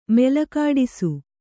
♪ melakāḍisu